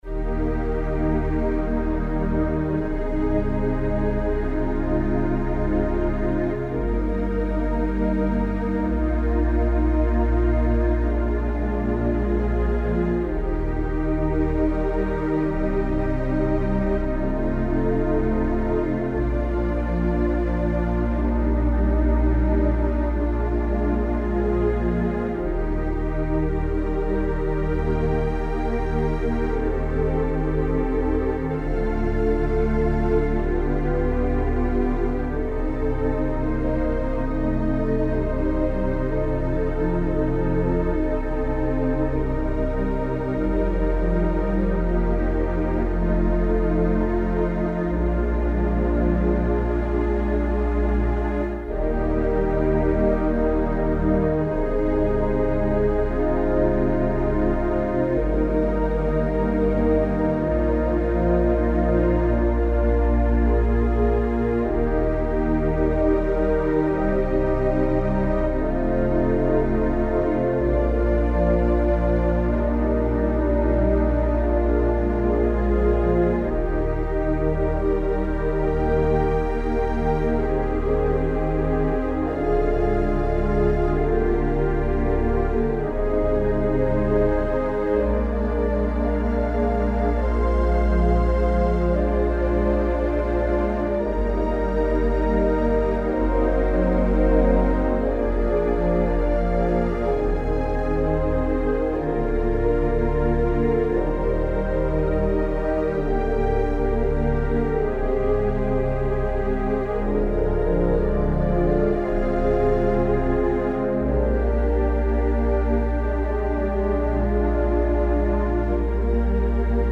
If we select a string sound at standard pitch and play in ensemble style i.e. both hands on the same keyboard we achieve the desired effect, the pedal and left hand providing the double bass and cello parts with the right hand providing the viola and violin. The same technique also applies for the construction of the brass section.